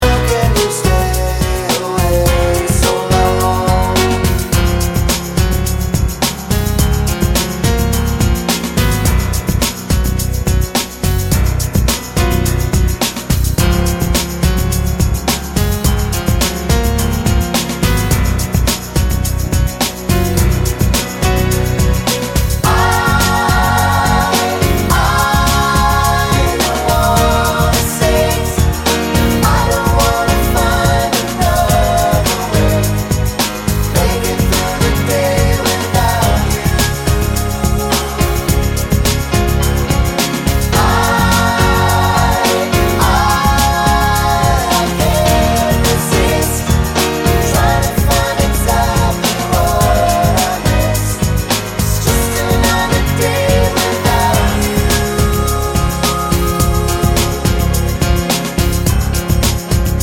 Double End Chorus with No Backing Vocals Pop